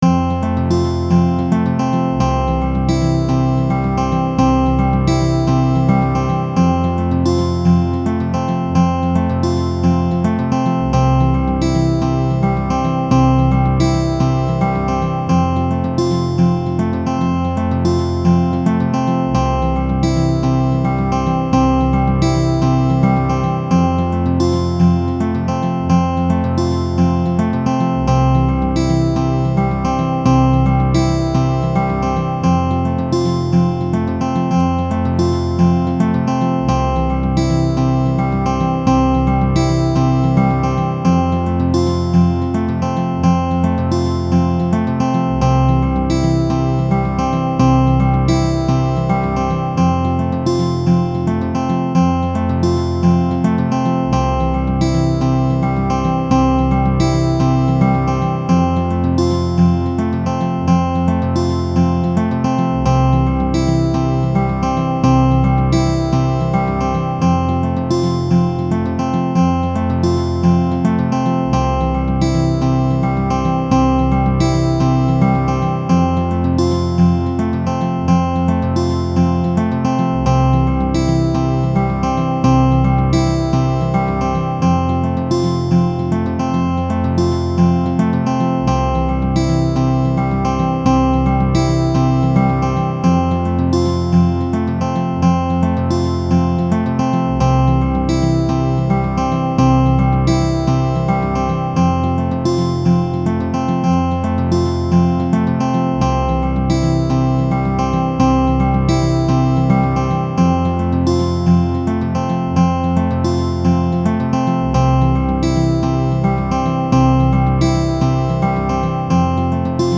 Major Tonic and Dominant Loop
major-tonic-dominant-loop-with-bass